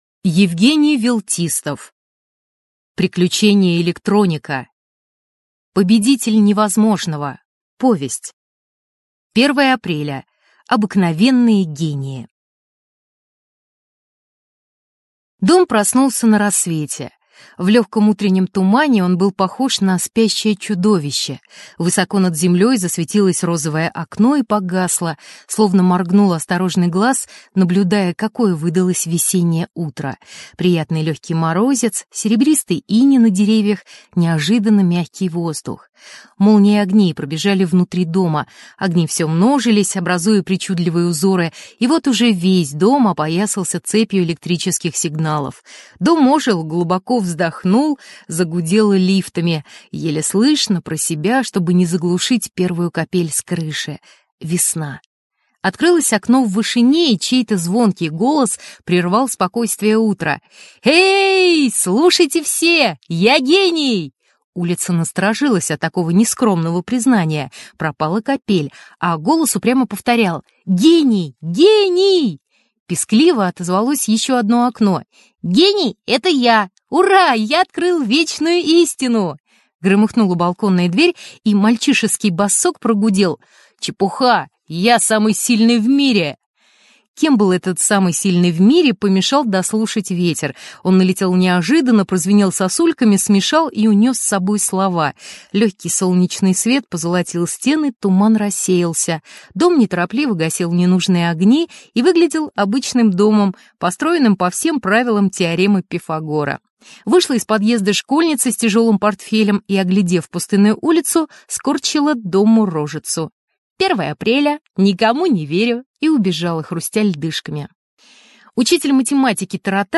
На данной странице вы можете слушать онлайн бесплатно и скачать аудиокнигу "Победитель невозможного" писателя Евгений Велтистов.